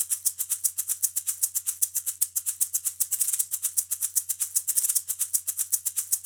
Ampliación de los bancos de sonido digitales de los instrumentos percutidos del folclor Colombiano
Joropo.wav
Folclor de Colombia, Regiones de Colombia, Instrumentos de percusión, instrumentos musicales